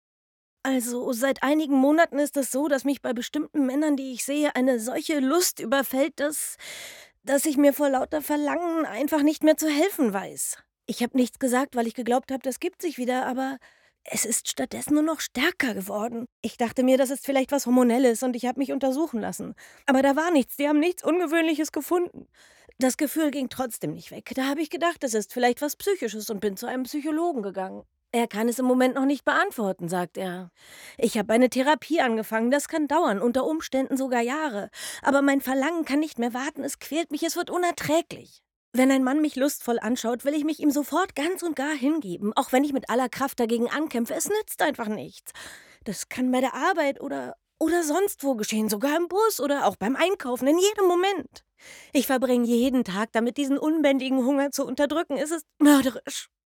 markant, dunkel, sonor, souverän, plakativ
Mittel minus (25-45)
Eigene Sprecherkabine